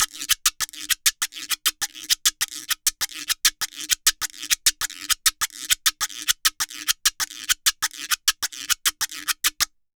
Guiro_Salsa 100_1.wav